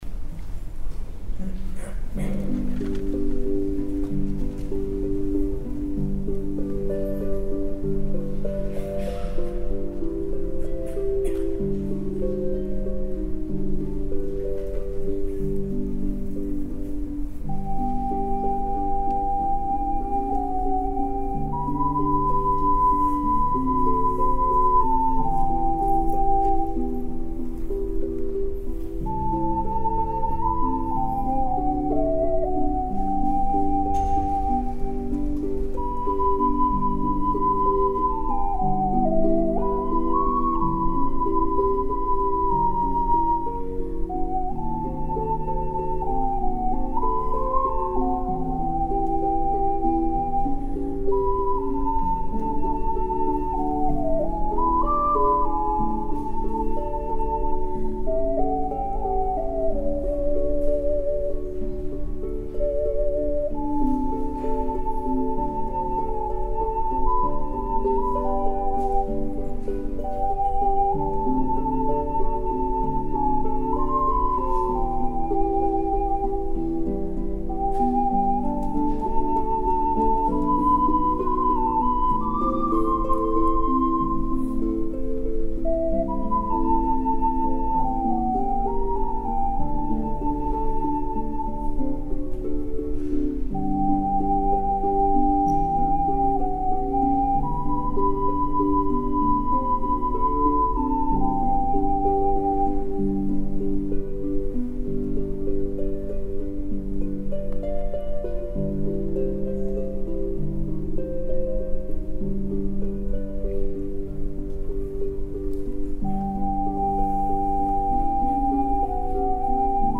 〜主に本番の録音です。自分の備忘録として〜
2011年11月 おがわオカリーナフェスティバルin宇都宮
ろまんちっく村ローズハット